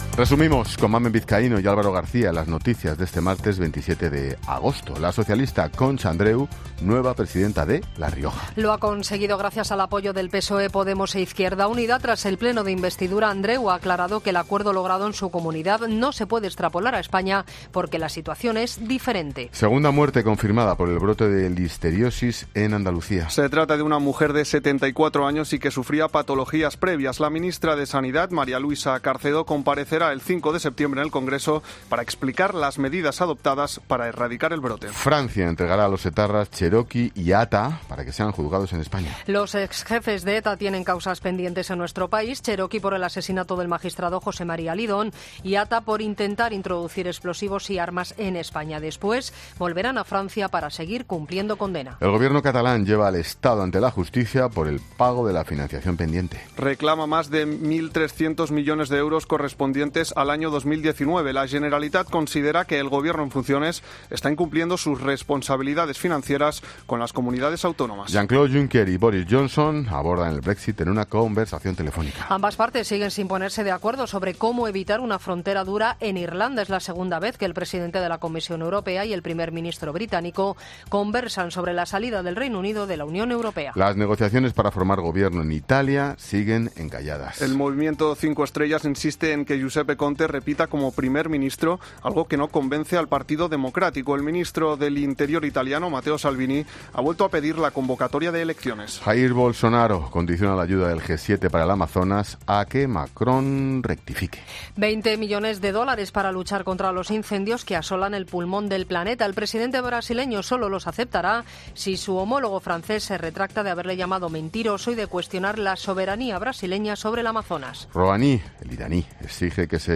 Boletín de noticias Cope del 27 de agosto a las 20.00 horas